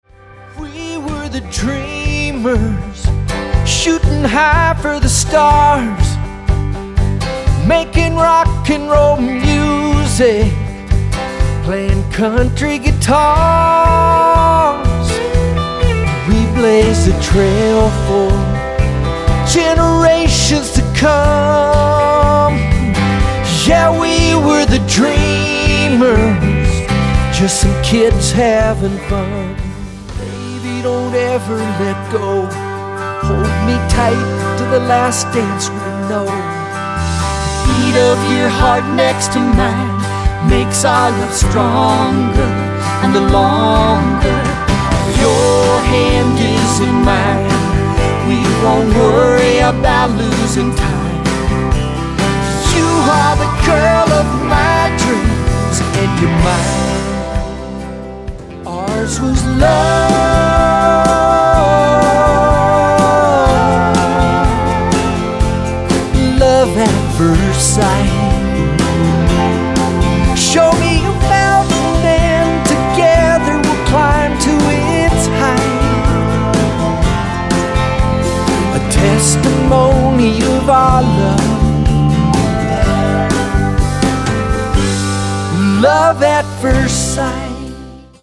mandolin, fiddle
guitar